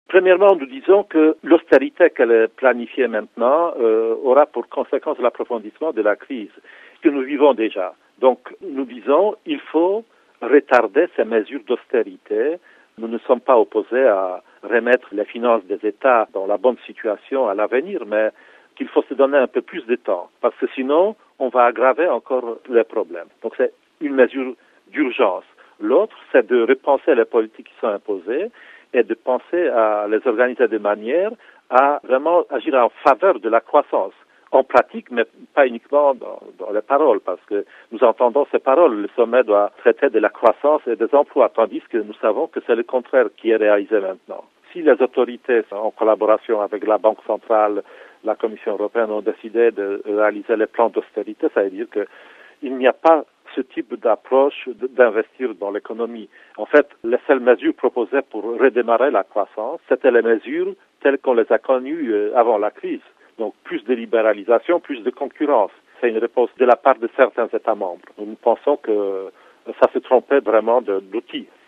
Interrogé